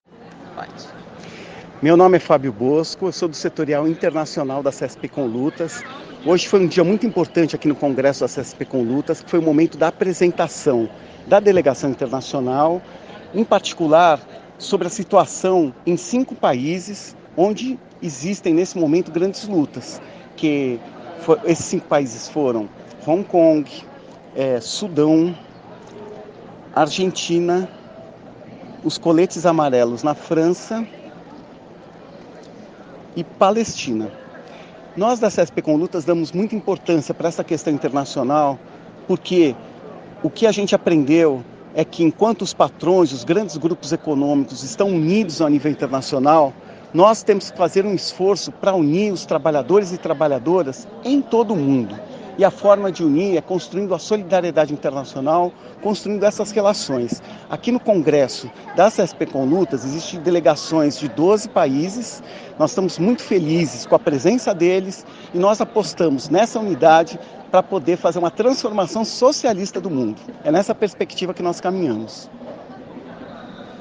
4º CONGRESSO DA CSP CONLUTAS